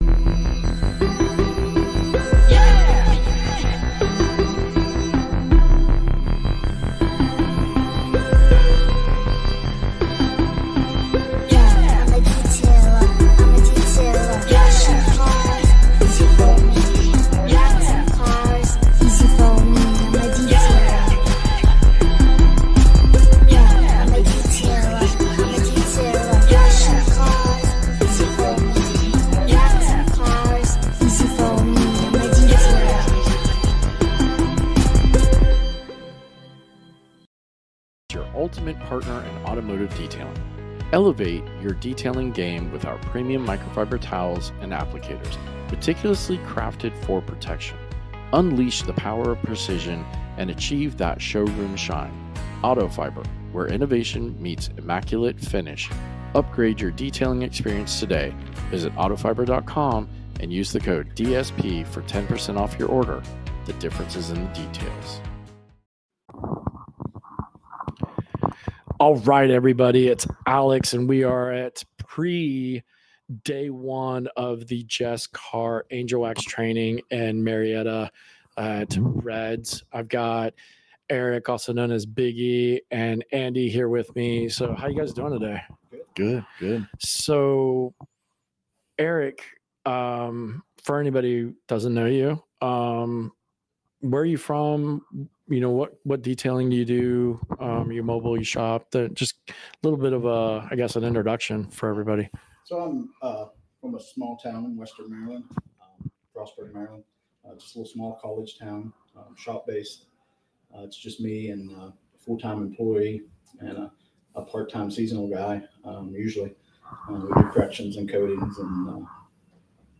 Well, too bad because in this episode you'll hear first hand from some attendees on what it was like along with some audio from the class.